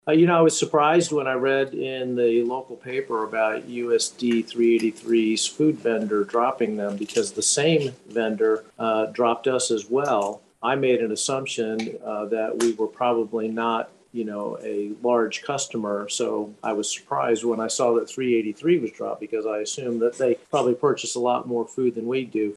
Butler shared his comments during Monday’s virtual intergovernmental luncheon, hosted by Riley County.